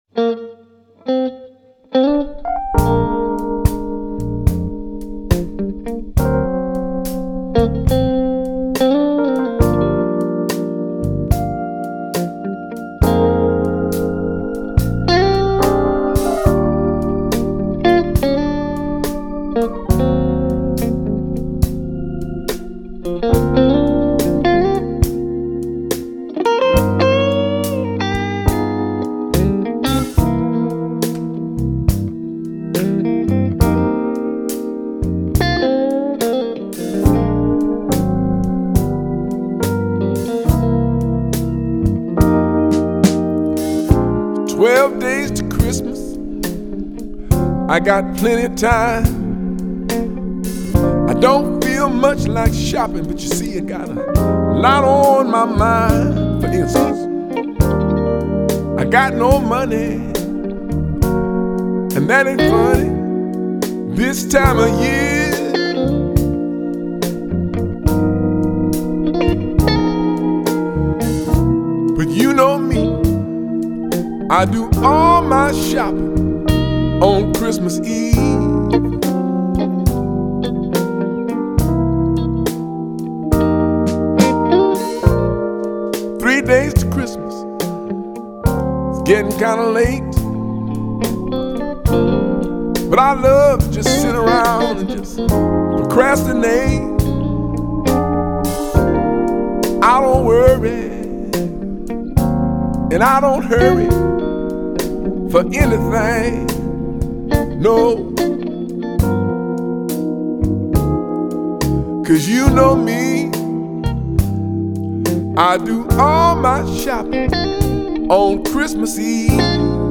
Жанр: Contemporary Blues